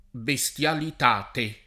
bestialità
bestialità [ be S t L alit #+ ] s. f.